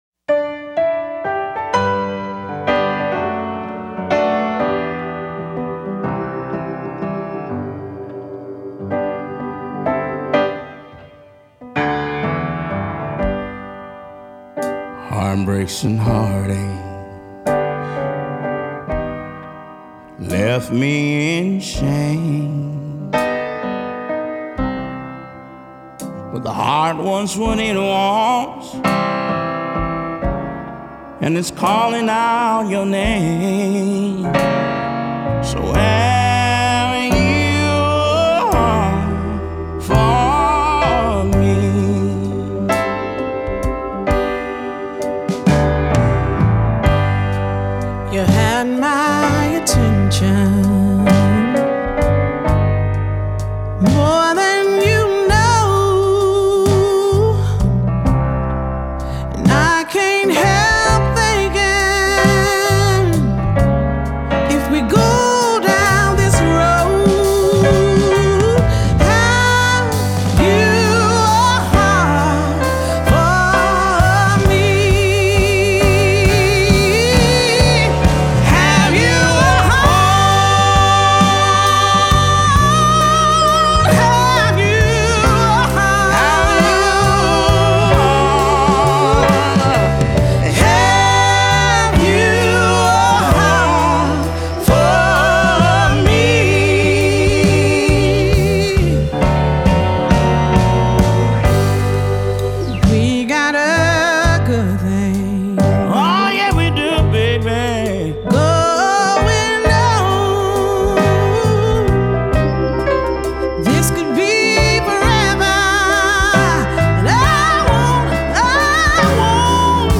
Жанр: soul, gospel, blues rock